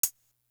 Legacy Hat.wav